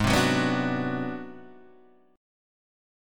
G# 7th Sharp 9th Flat 5th